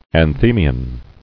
[an·the·mi·on]